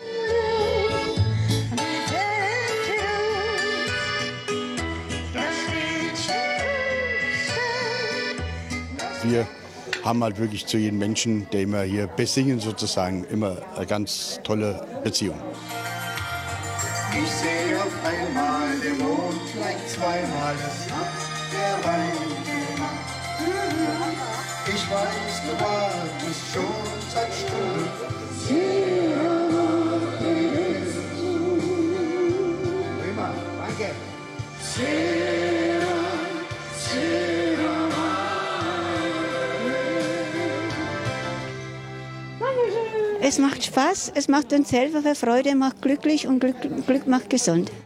Und hier für die ganz Eiligen: Die Kurzfassung vom Auftritt in Höchstadt !